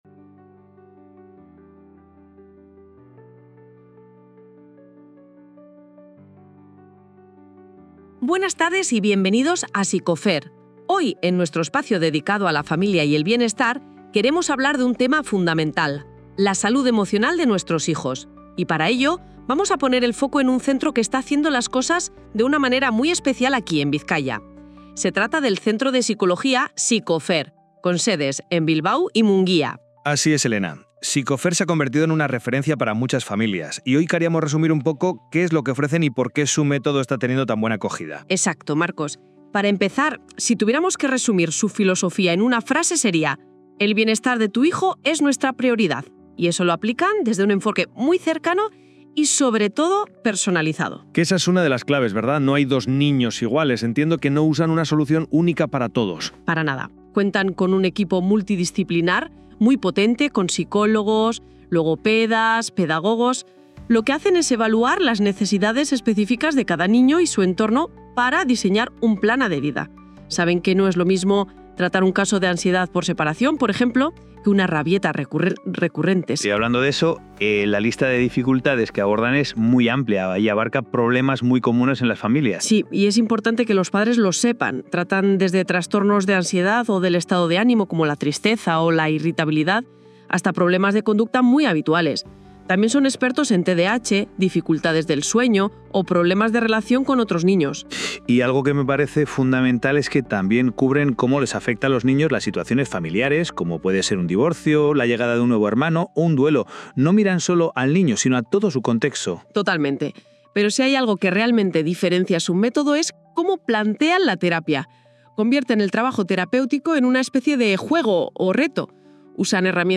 Si no te apetece leer, en este episodio de nuestro podcast te resumimos todo lo que necesitas saber sobre nuestro enfoque de terapia infantil en una conversación clara y cercana.